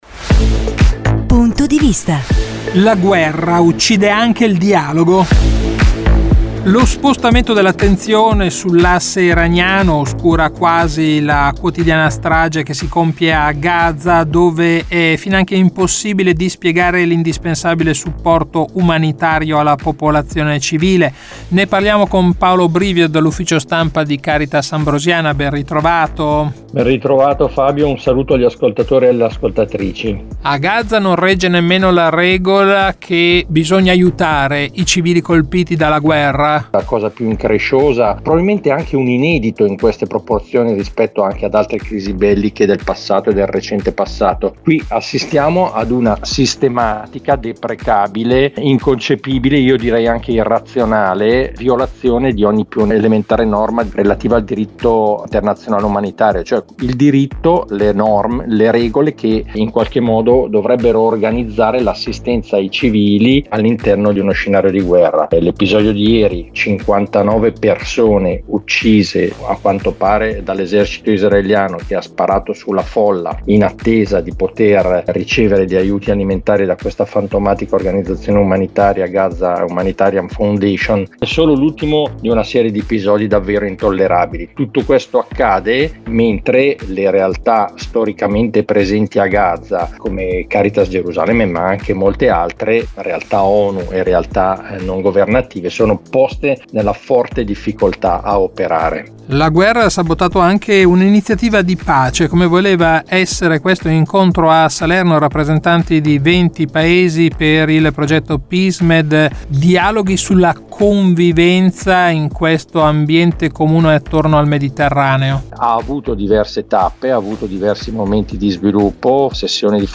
ANALISI